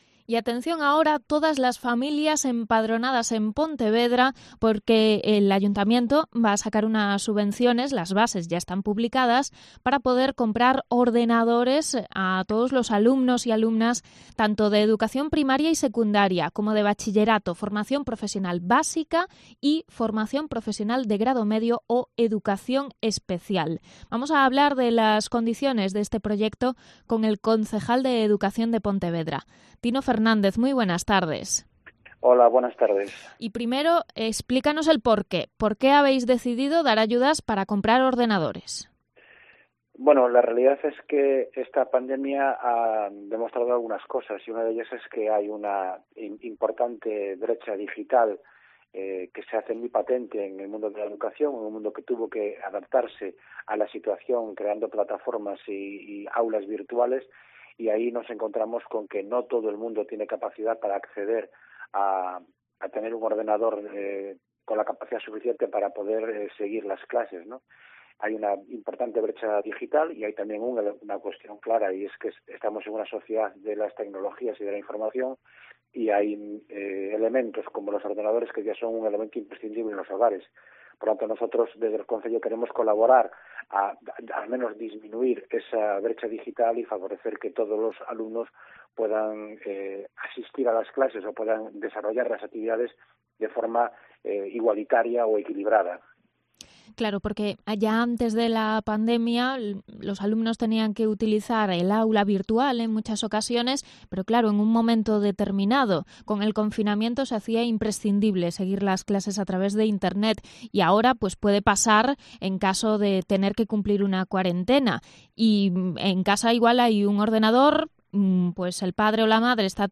El concejal de Educación, Tino Fernández, sobre las subvenciones a compra de ordenadores para estudiantes